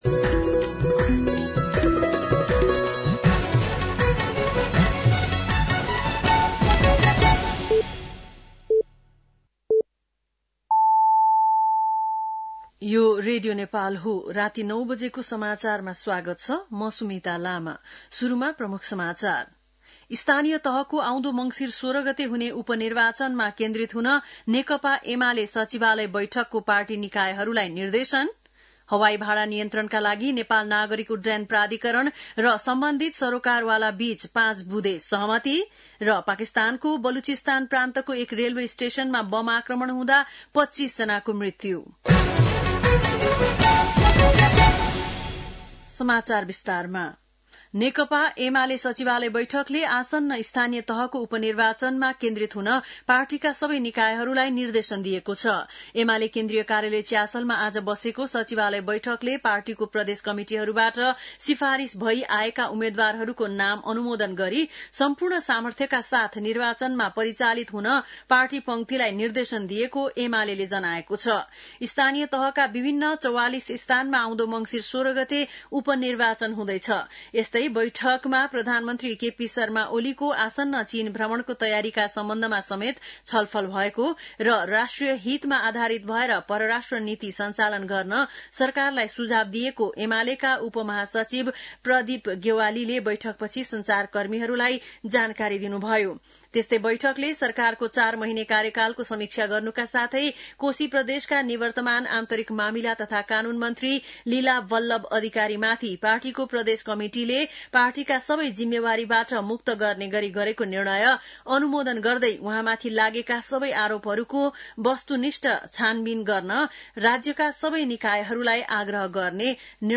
बेलुकी ९ बजेको नेपाली समाचार : २५ कार्तिक , २०८१